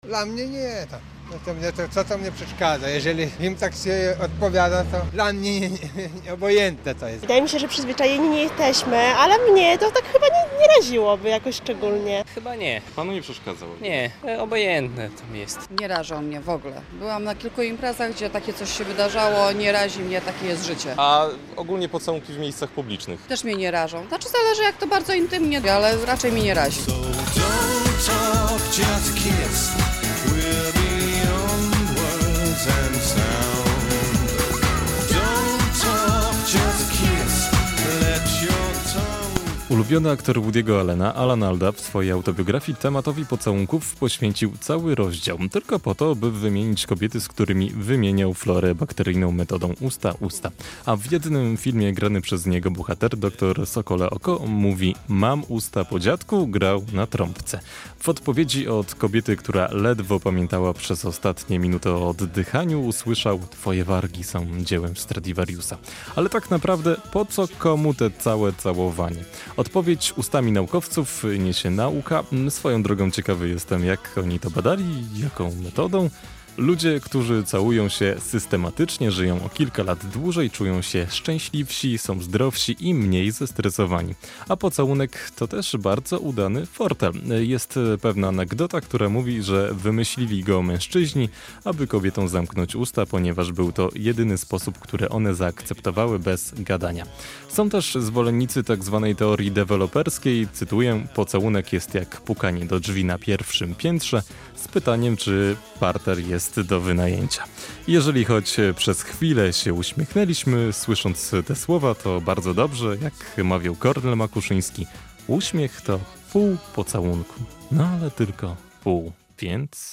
Dzień Pocałunku - relacja